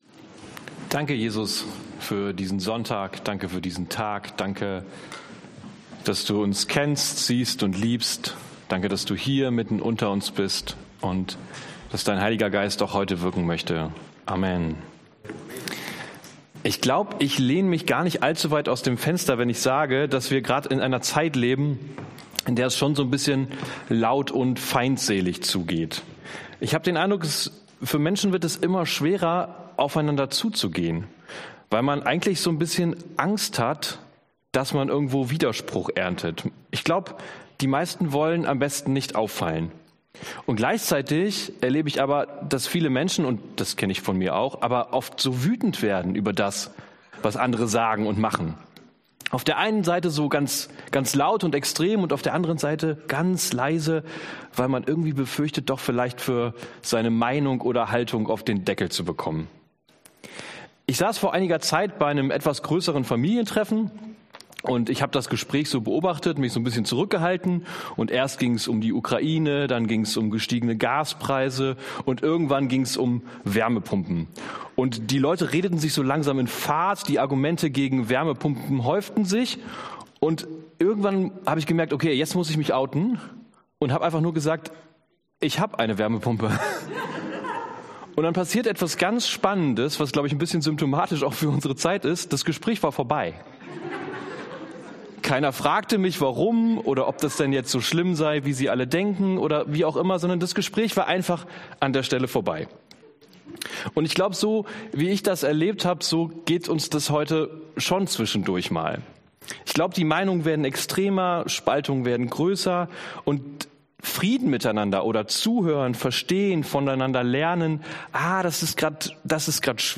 Entfache sie neu in dir Dienstart: Predigt Themen: Gemeinschaft , Heiliger Geist « Ein Geist der Kraft